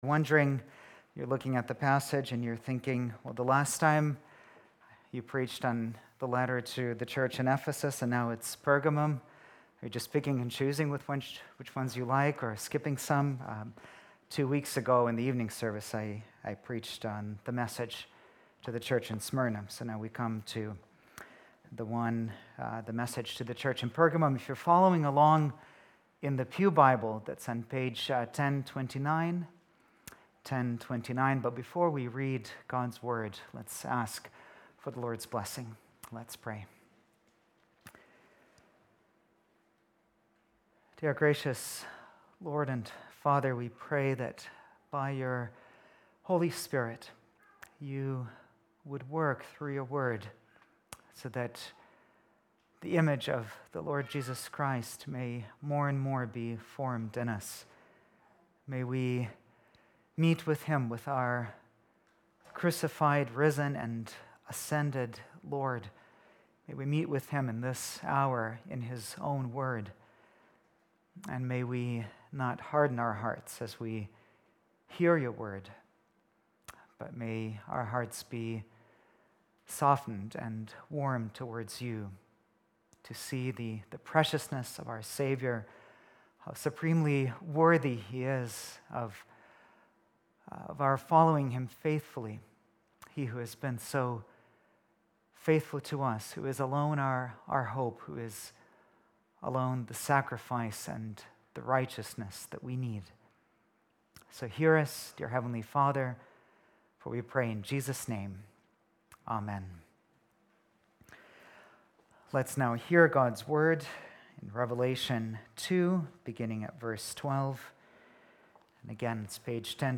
Bible Text: Revelation 2:12-17 | Preacher: